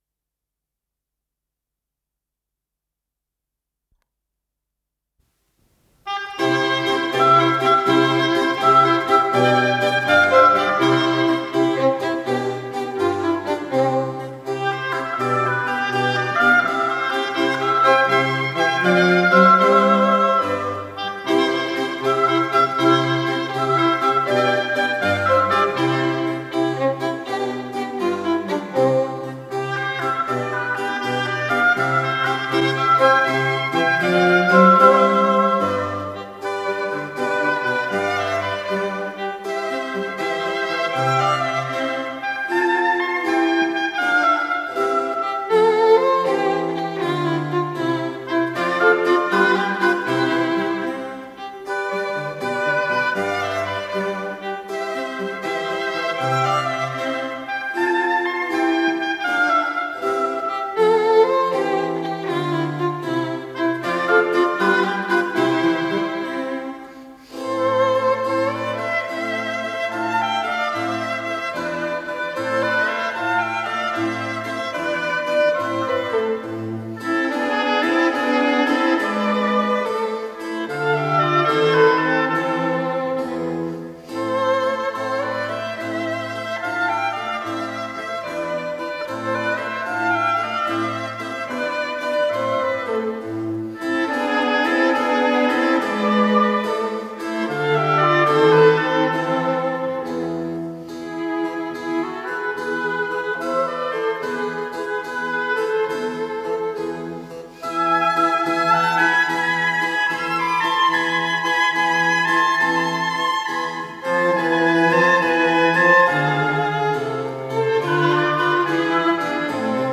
ИсполнителиКамерный ансамбль "Концертино"
Скорость ленты38 см/с
ВариантДубль моно